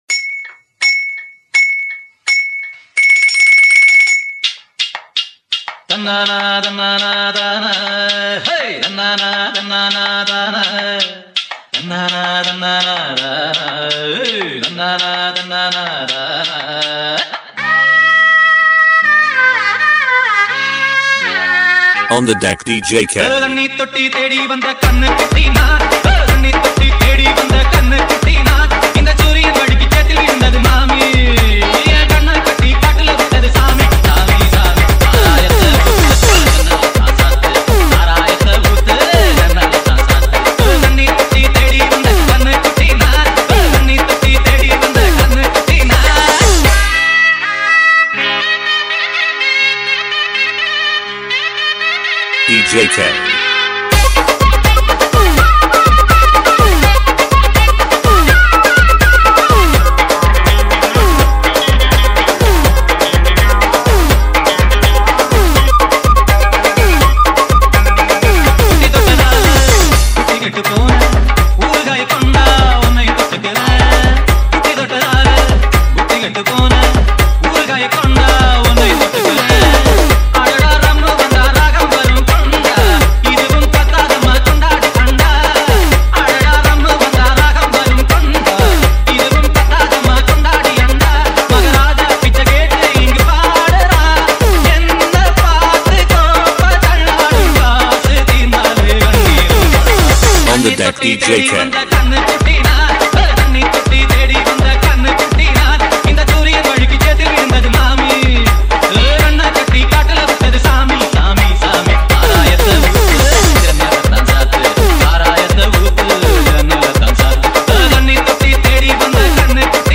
ALL TAMIL LOVE DJ REMIX